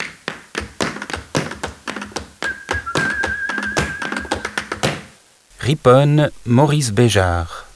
Die Geräusche aus den Regionen Lausanne und Alpes vaudoises, haben Sie bestimmt alle erraten.